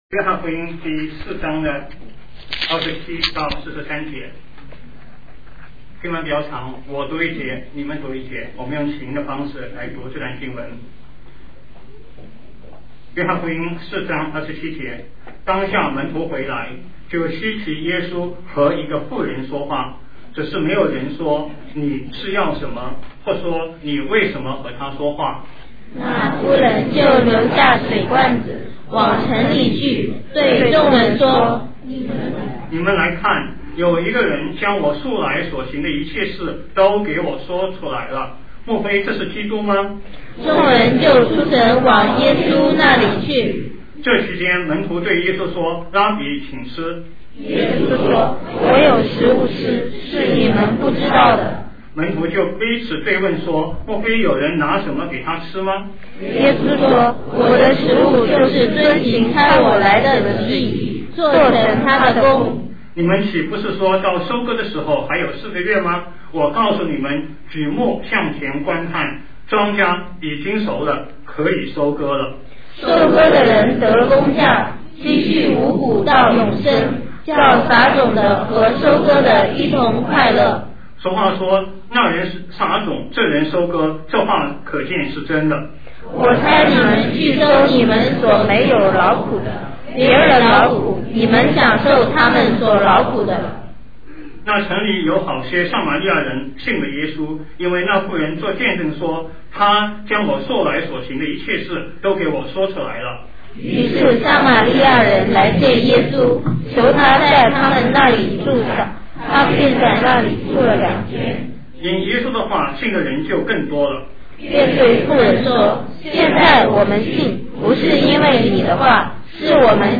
复活节讲道：我差你们去（2006年4月16日，附音频）